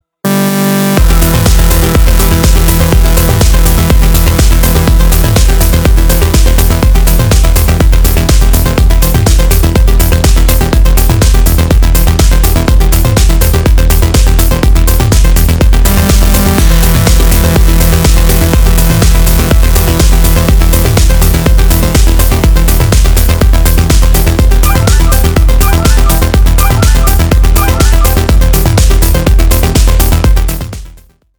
• Качество: 320, Stereo
громкие
электронная музыка
techno